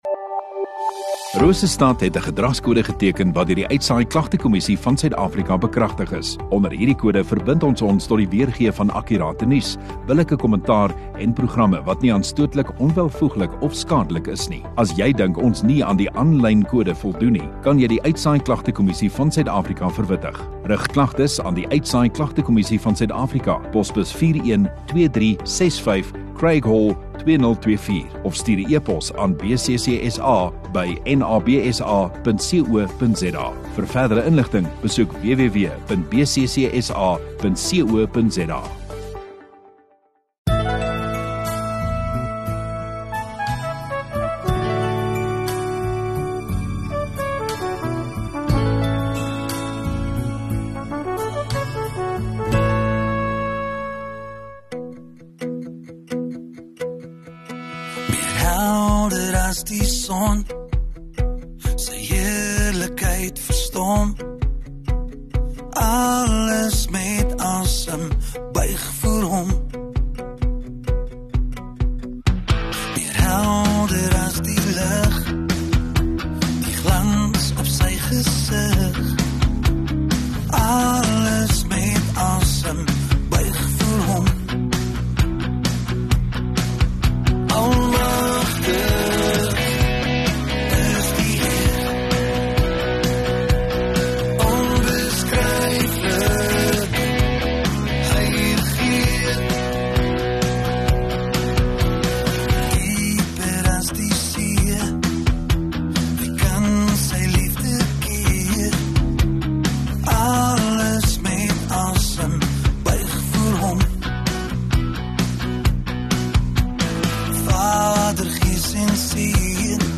21 Apr Sondagaand Erediens